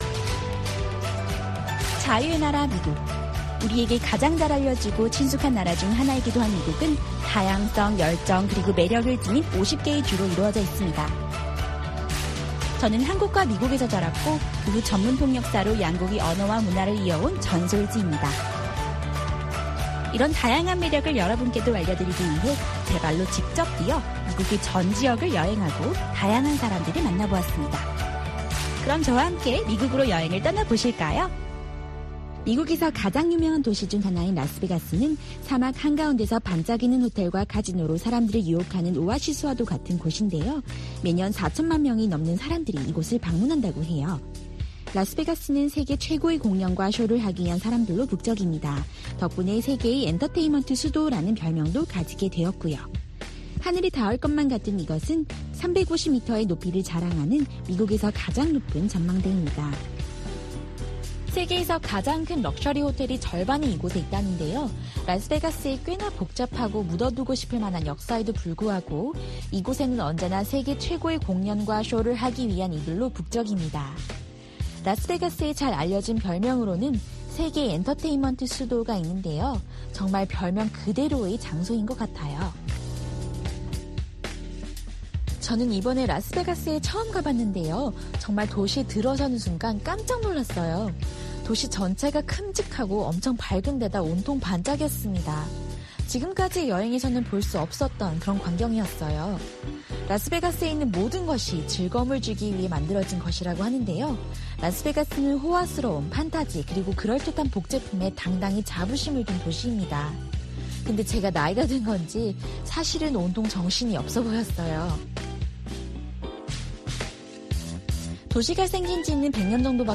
VOA 한국어 방송의 일요일 오전 프로그램 2부입니다.